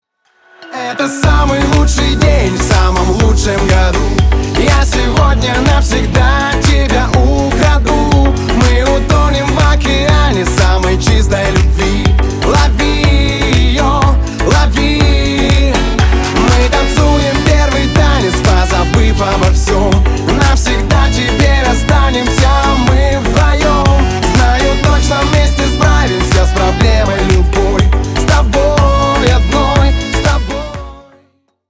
поп
позитивные
праздничные